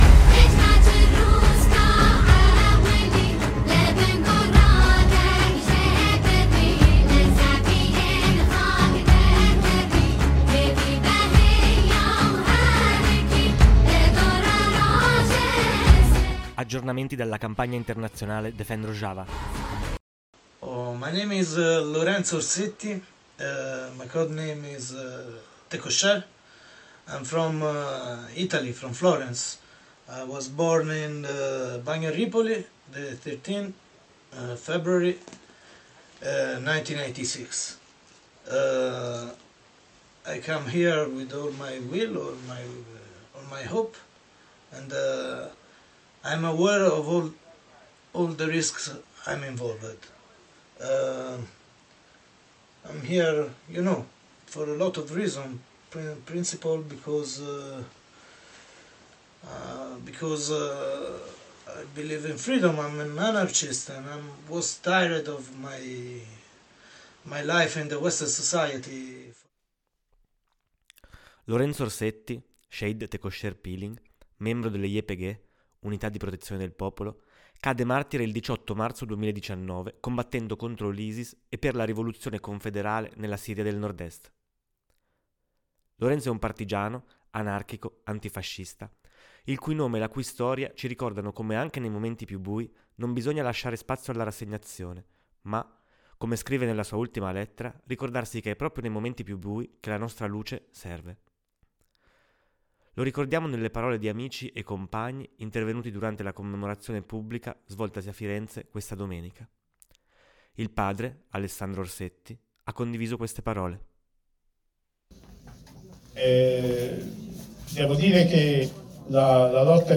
Lo ricordiamo nelle parole di amici e compagni intervenuti durante la commemorazione pubblica svoltasi a Firenze questa domenica. A seguire, gli ultimi aggiornamenti dall’Amministrazione Autonoma della Siria del Nord Est